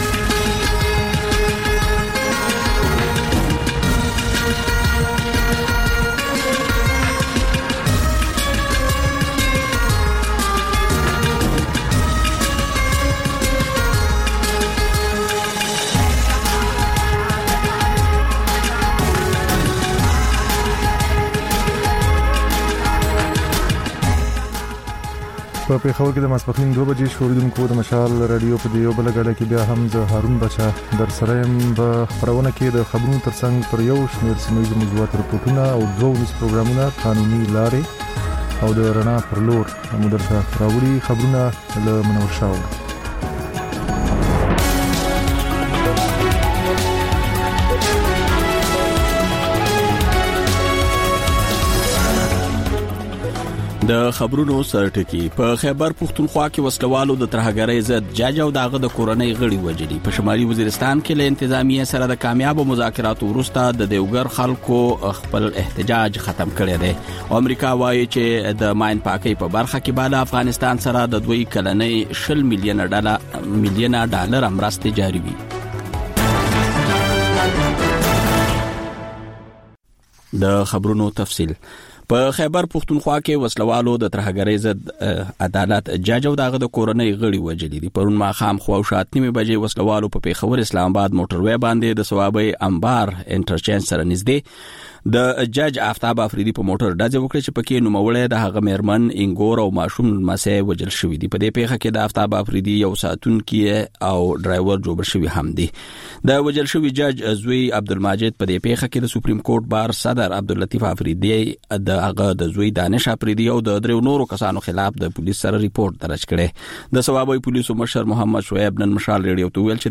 د مشال راډیو دویمه ماسپښینۍ خپرونه. په دې خپرونه کې تر خبرونو وروسته بېلا بېل رپورټونه، شننې، مرکې خپرېږي.